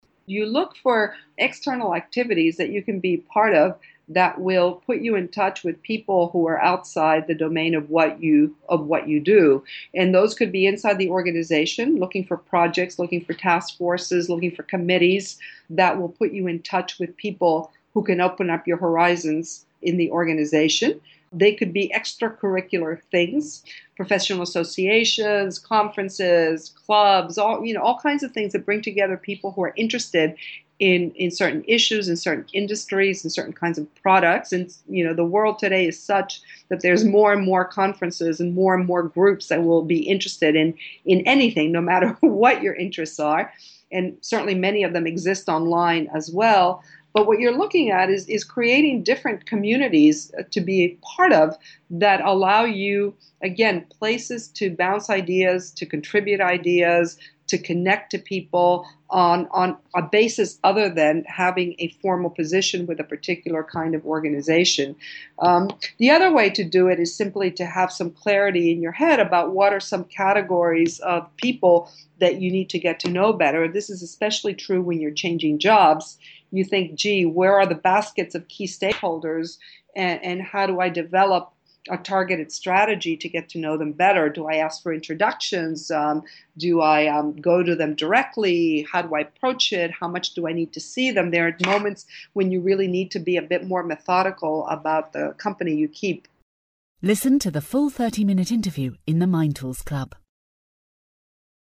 In our Expert Interview podcast , Ibarra talks in depth about the second of these areas: your network.